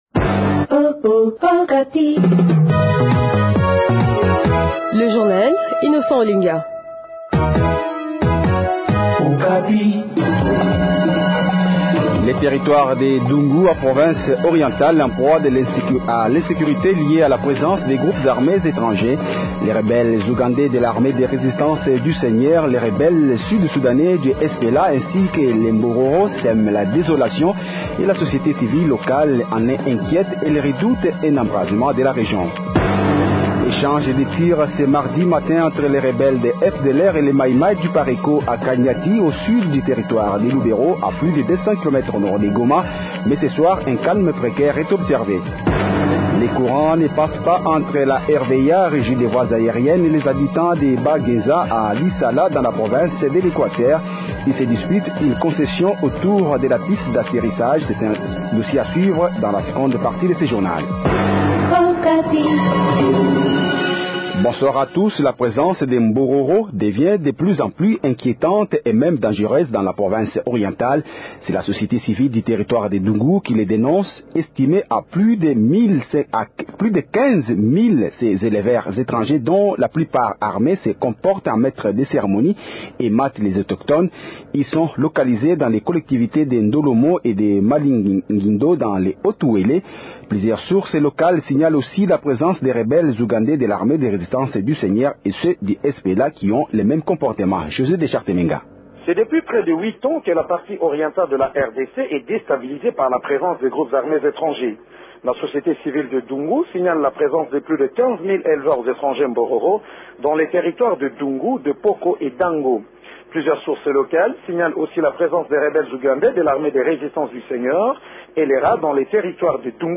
Journal Francais Soir